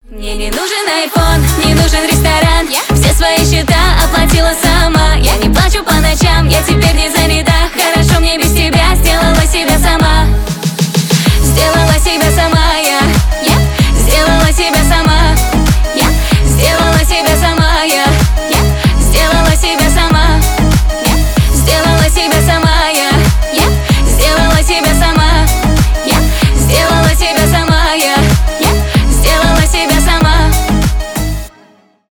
женские
поп